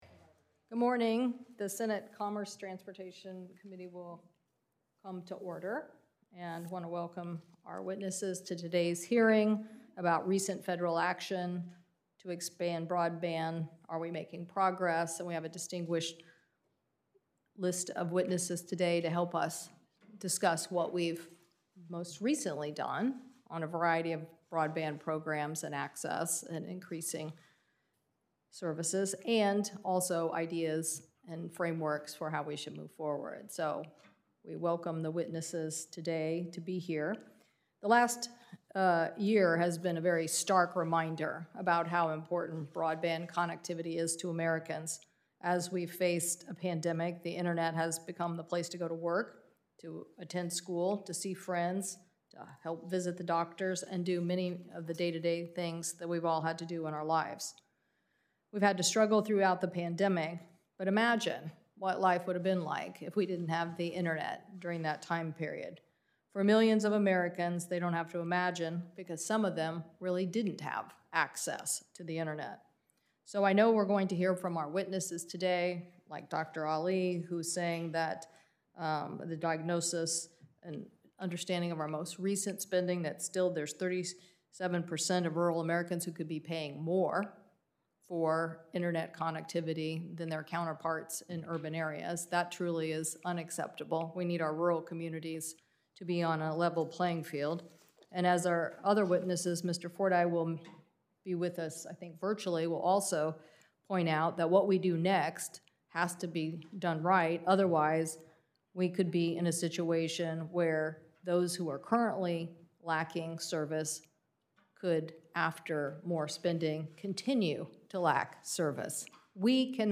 Video of Chair Cantwell’s opening statement can be found HERE and audio is HERE.